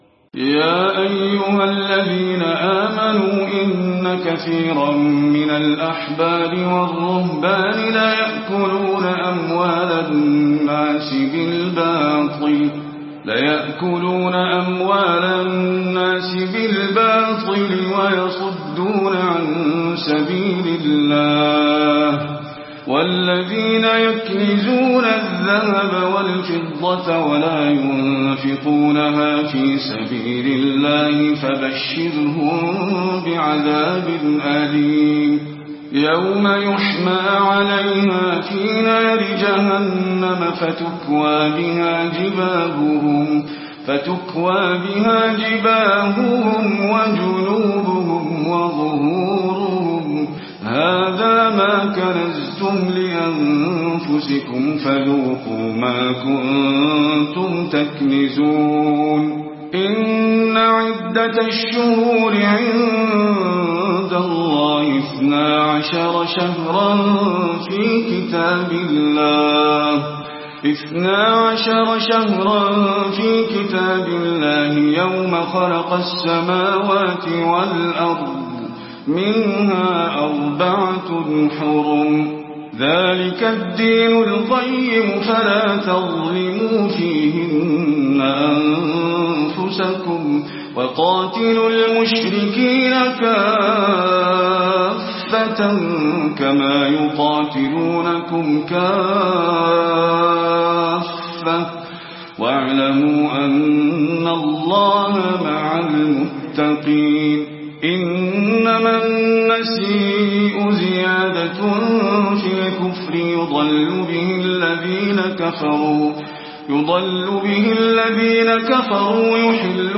تلاوة سورة التوبة من آية 34 إلى 99
المكان: المسجد النبوي